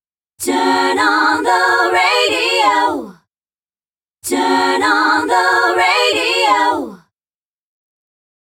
To avoid any messy copyright issues I’ve used a vocal phrase from Apple’s ‘Voices’ Jam pack.
The vocal part.